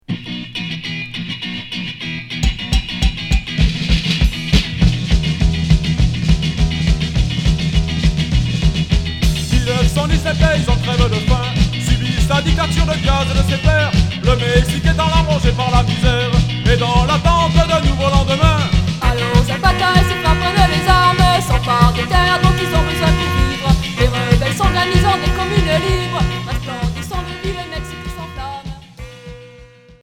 Anarcho punk Premier 45t